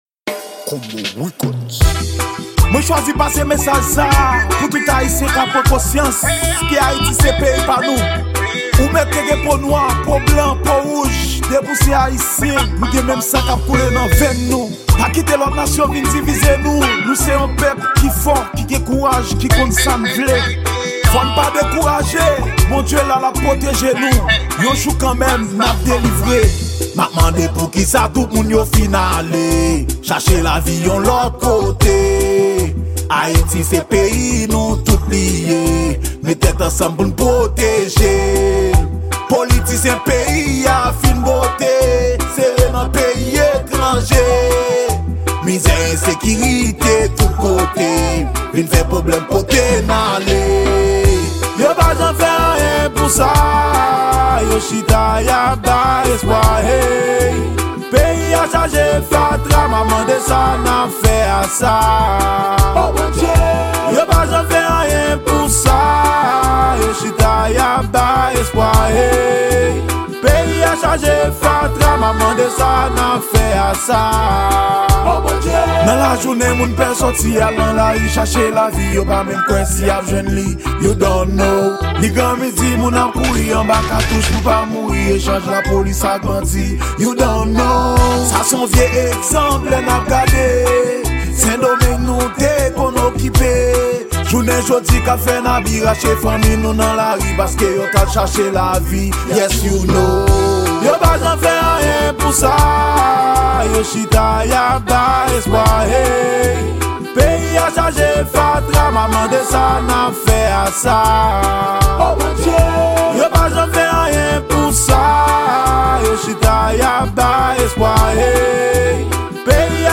Genre: Reggea.